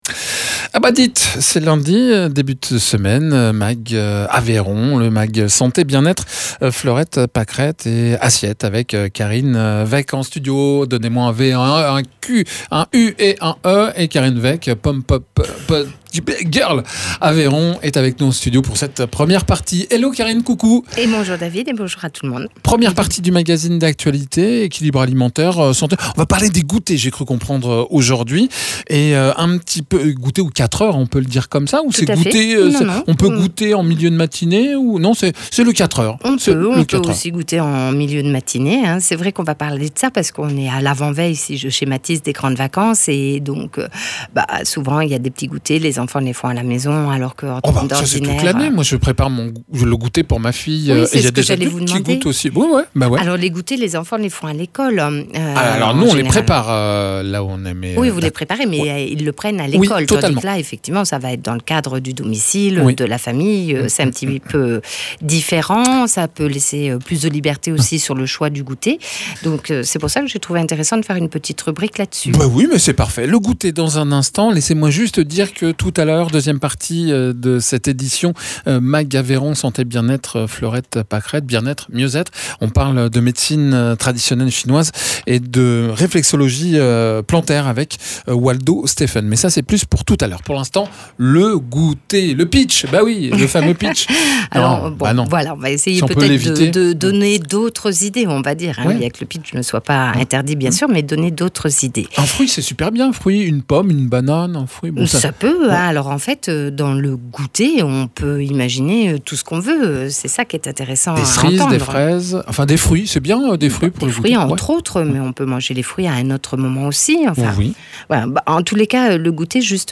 nutritionniste diététicienne
praticien en réflexologie plantaire et Médecine Traditionnelle Chinoise